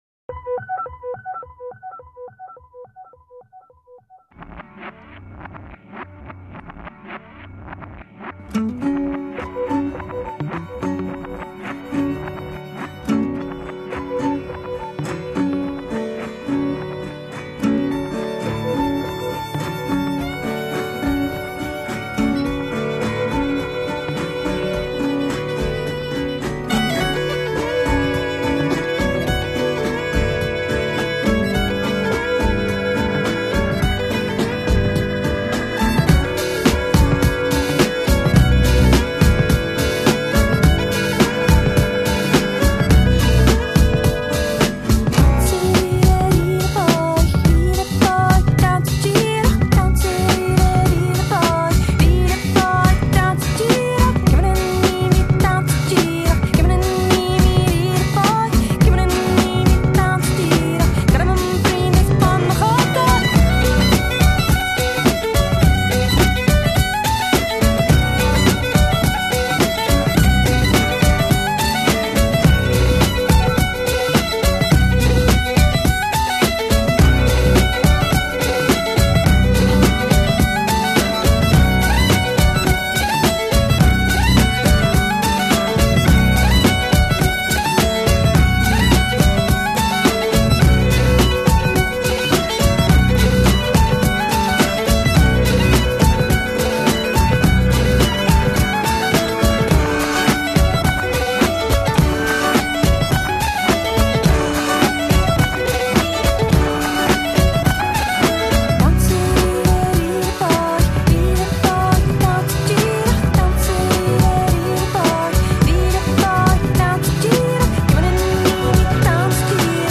Celtic/British Isles